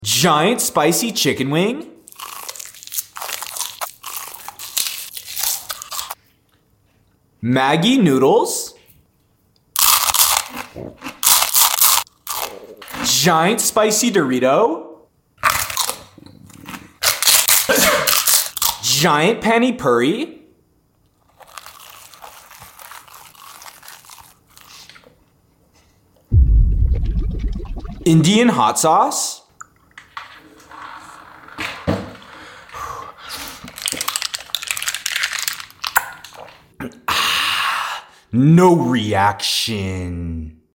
Spicy Indian Food ASMR 🇮🇳🥵 Sound Effects Free Download